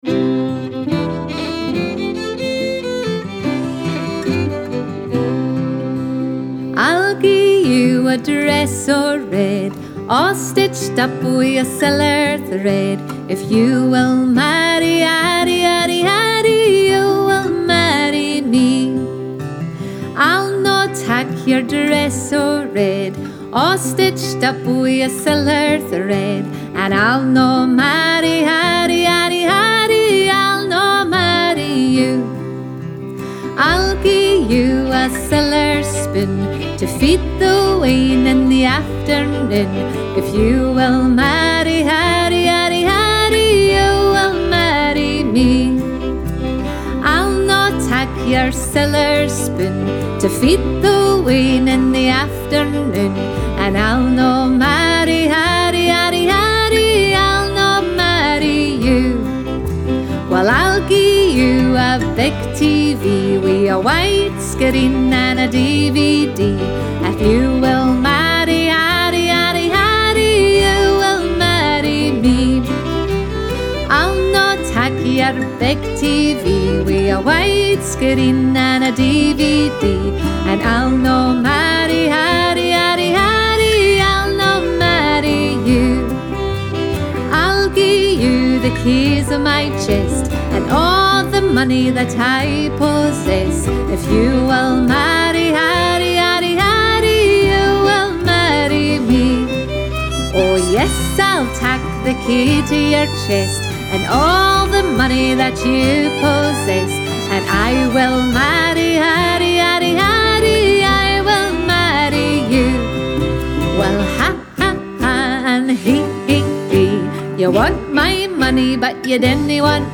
Scottish Music Download Dress O' Red MP3